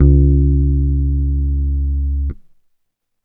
Index of /90_sSampleCDs/USB Soundscan vol.30 - Bass Grooves [AKAI] 1CD/Partition E/04-BASS MED